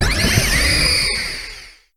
Grito de Tapu Lele.ogg
Grito_de_Tapu_Lele.ogg